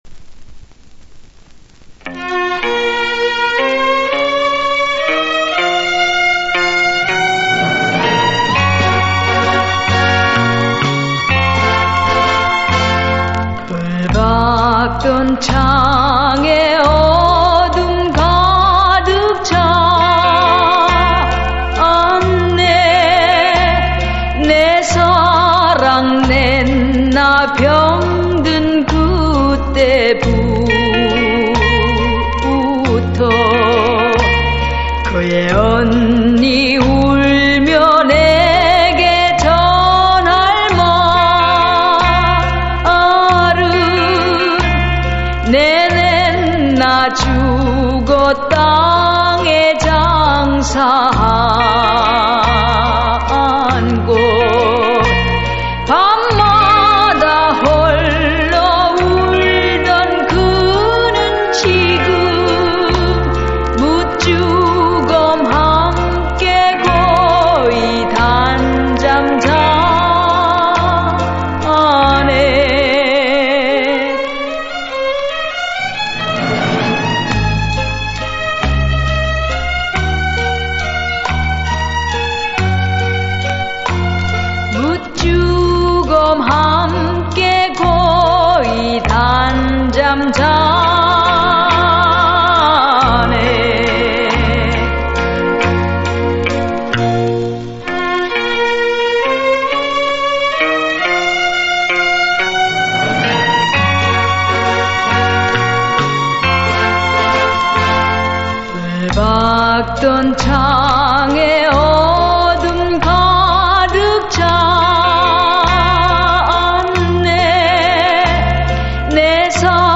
이 노래는 말씀하신대로 이태리 가곡인데